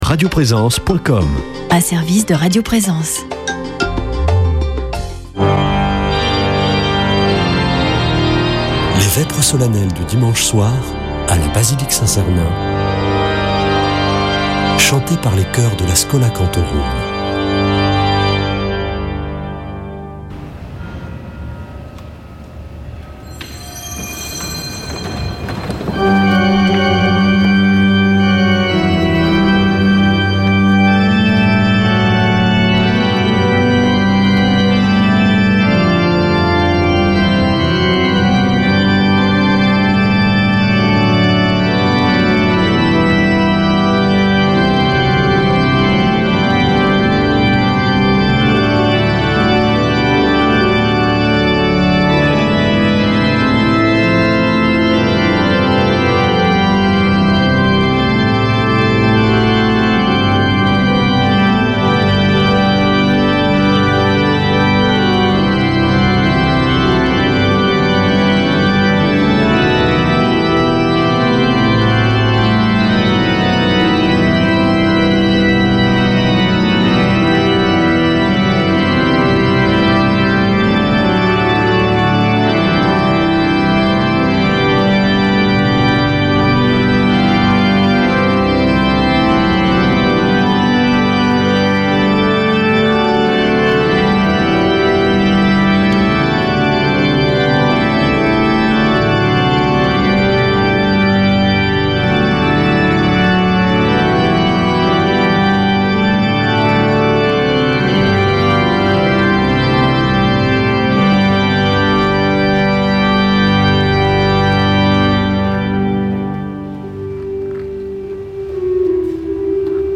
Vêpres de Saint Sernin du 04 juin
Une émission présentée par Schola Saint Sernin Chanteurs